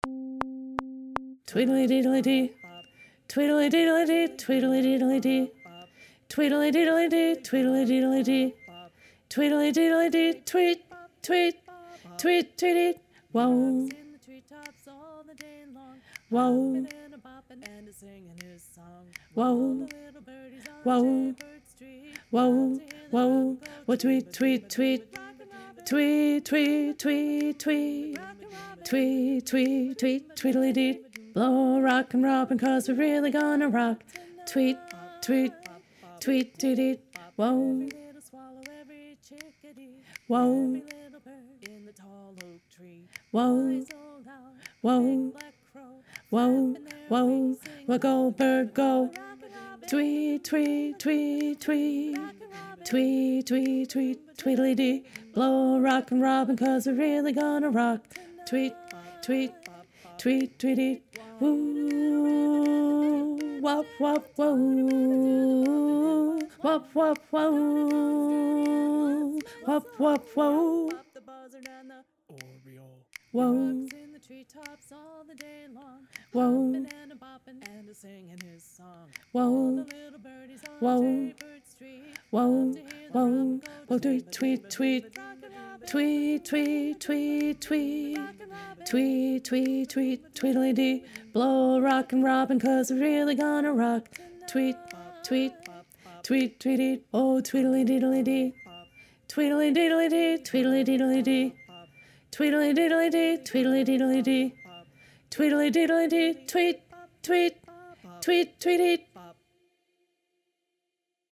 Alto 1 (Bari)